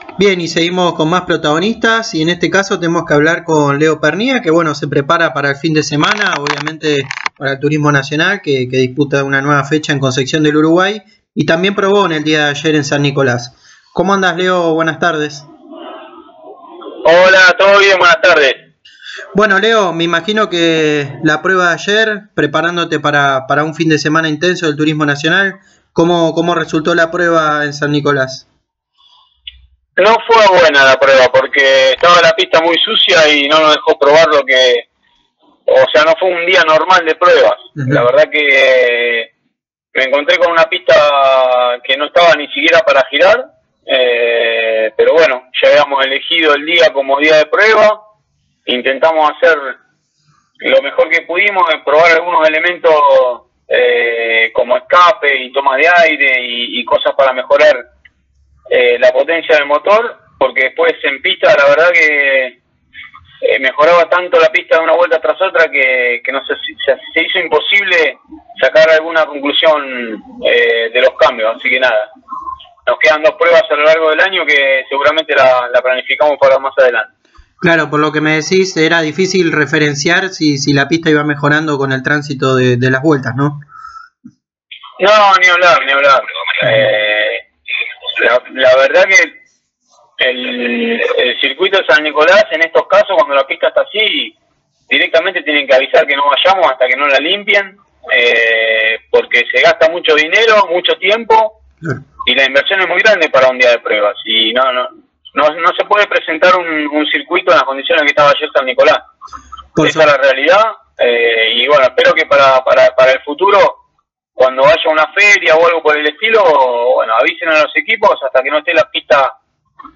El tandilense pasó por los micrófonos de Pole Position y habló de la prueba realizada que se hizo ayer en San Nicolás, donde no se puedo desarrollar con normalidad debido a las condiciones de suciedad en la que se encontraba el circuito.